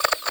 sci-fi_hacking_aliens_03.wav